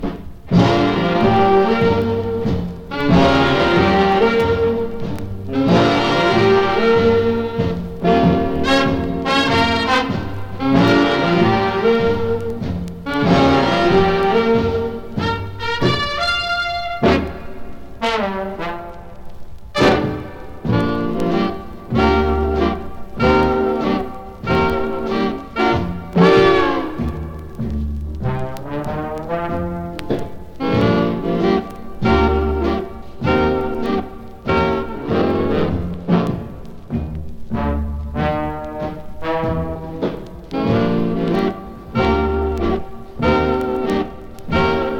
Jazz, Big Band, Swing　USA　12inchレコード　33rpm　Mono